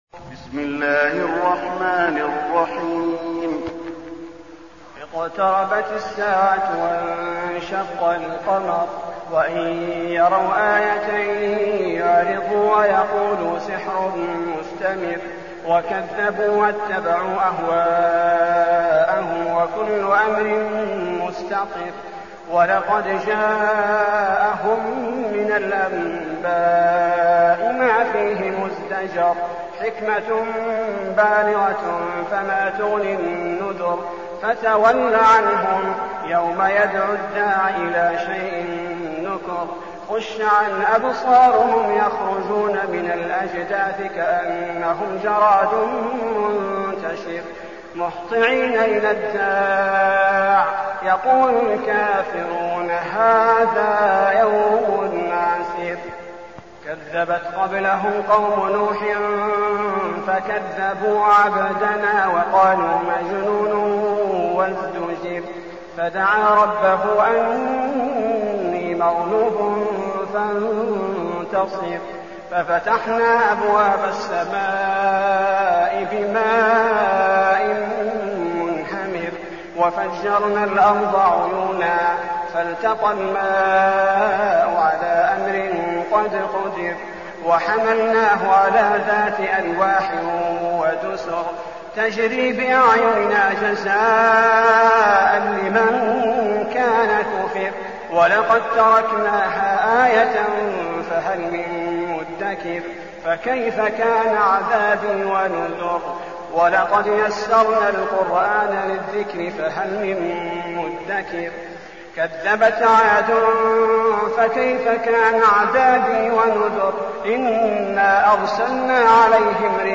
المكان: المسجد النبوي القمر